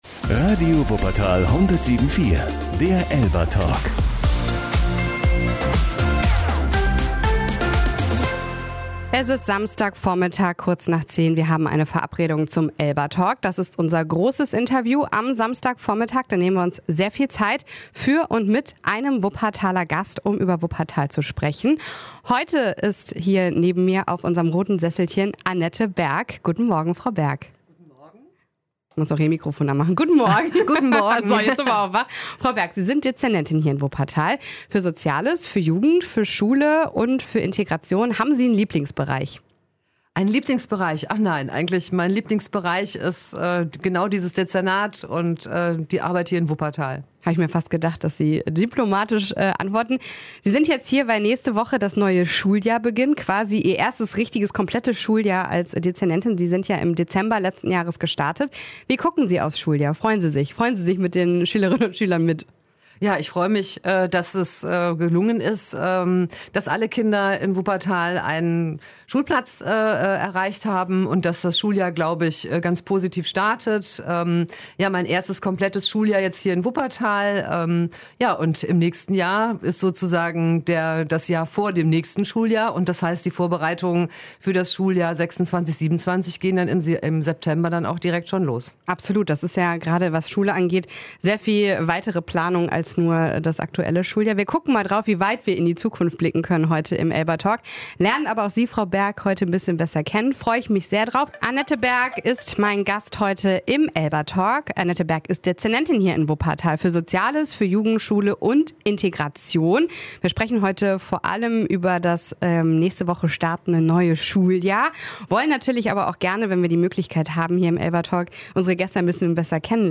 Interview: • Radio Wuppertal